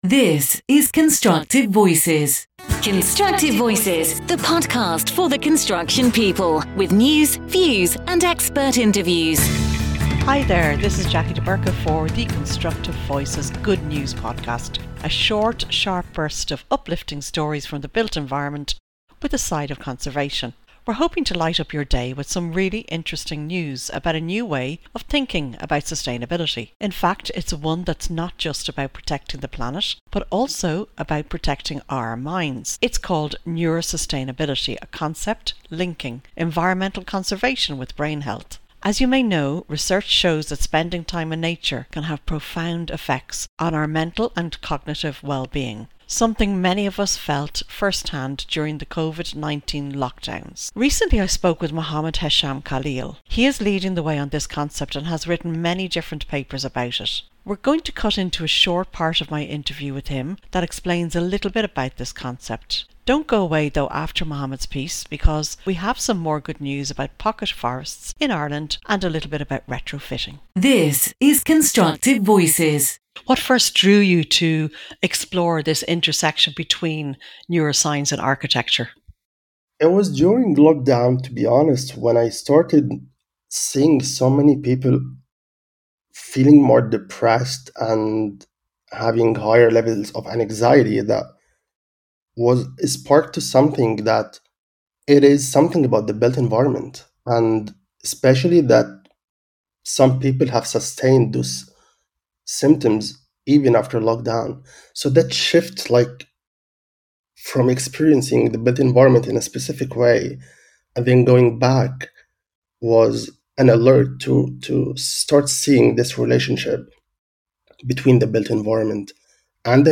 In this uplifting edition of the Constructive Voices Good News podcast in less than six minutes, we bring you a short, sharp burst of positive stories from the built environment — with a dash of conservation inspiration.